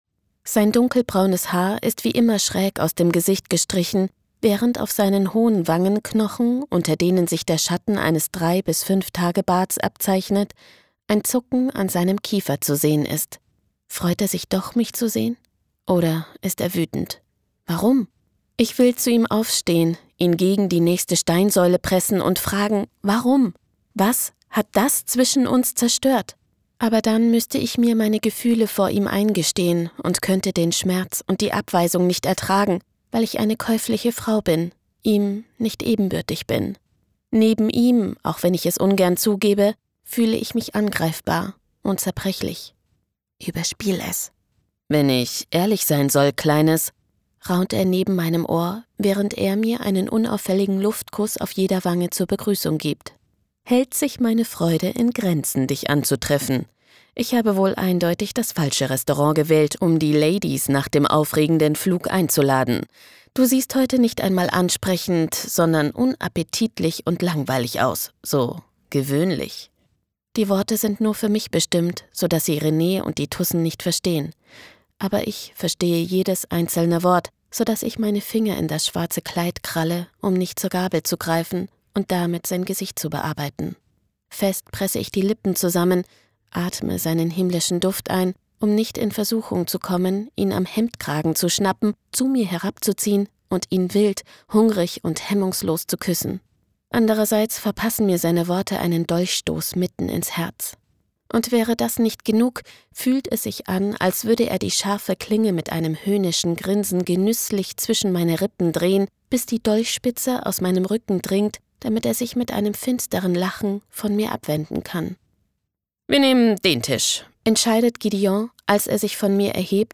Hörbuch SEHNSÜCHTIG Gefunden (Part 4)
Um euch einen Vorgeschmack auf das Hörbuch zu geben, könnt ihr die Hörprobe von 5 Minuten bereits anhören und mir gern eurer Feedback dalassen.
Ein Sprecherwechsel war unvermeidlich.